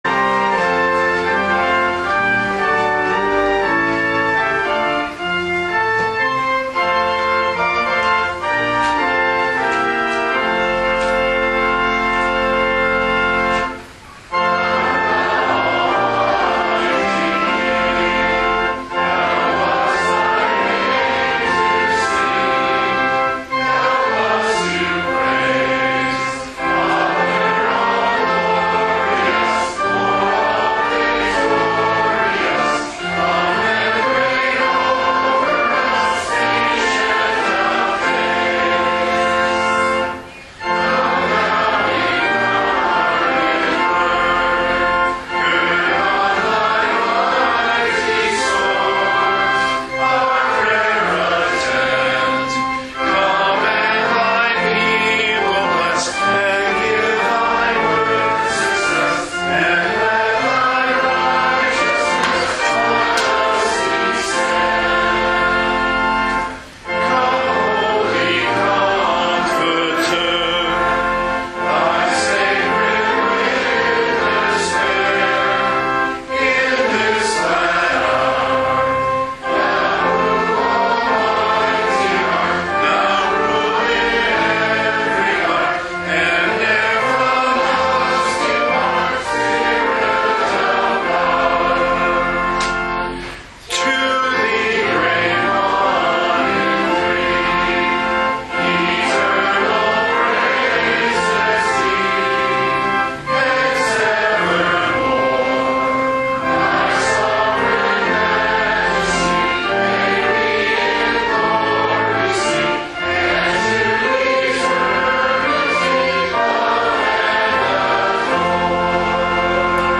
This weeks Sermon Audio